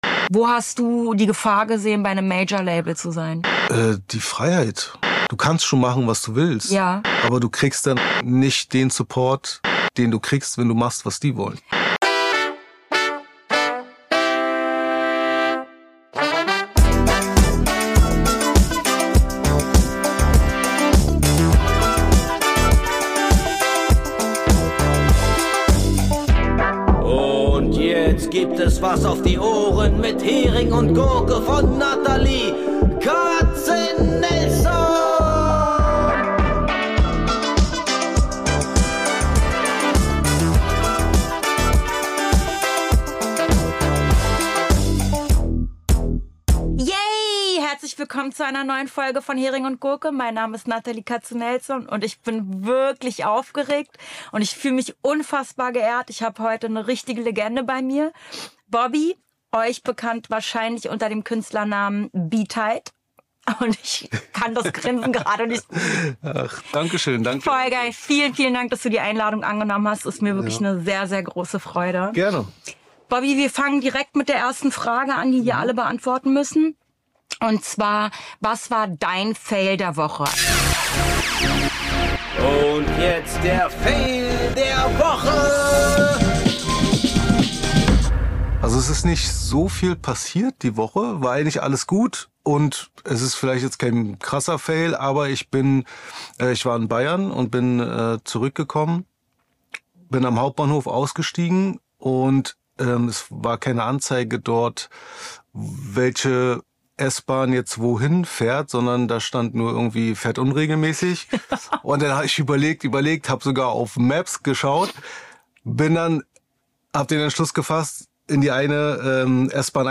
Ein Gespräch über den Menschen hinter dem Künstler. Über Wege, Umbrüche und das Unterwegssein zwischen Musik und Leben.